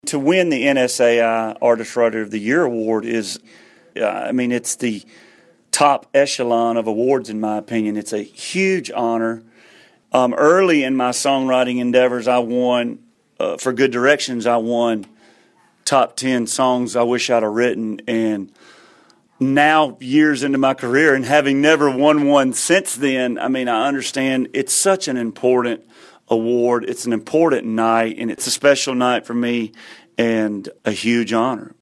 Audio / Luke Bryan talks about winning the NSAI Artist-Songwriter Award of the Year.